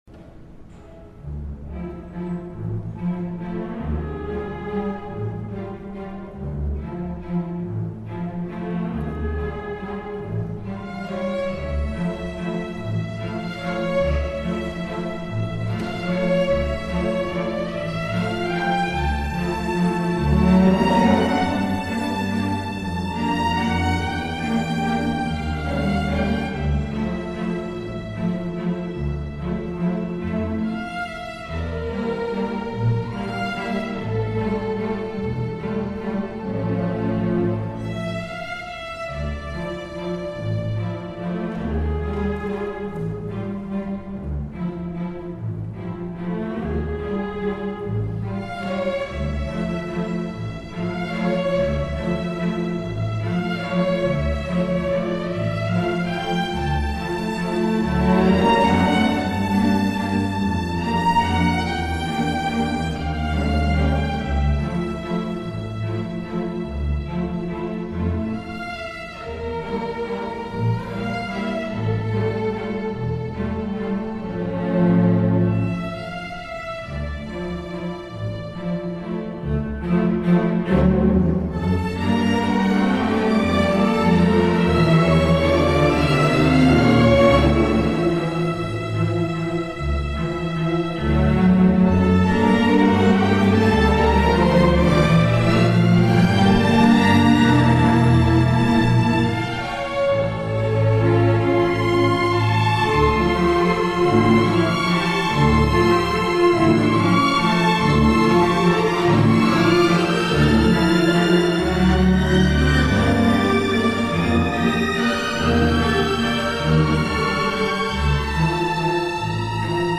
Style: Macabre Waltz
Standard String Orchestra